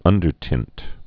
(ŭndər-tĭnt)